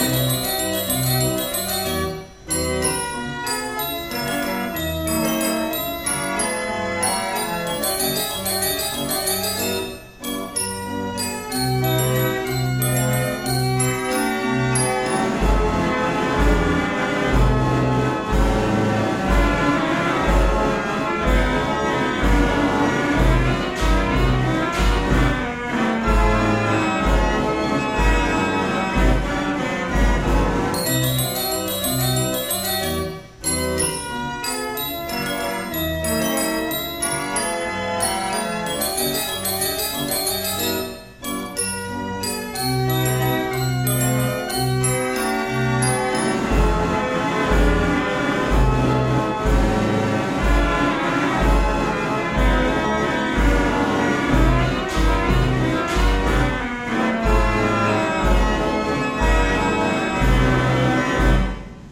Wurlitzer 165 band organ sound files
A waltz, probably from the 1923-1926 era, on roll 6636, issued in mid-1926. This excerpt was recorded from the Wurlitzer 165